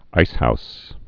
(īshous)